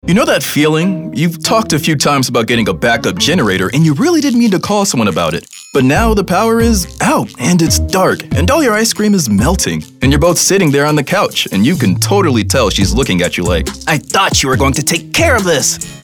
Their upbeat, friendly vibe brings scripts to life! they nail commercials, audiobooks, and animation with authentic charm.
announcer, confident, cool, friendly, informative, mature, millennial, promo, retail, thoughtful, upbeat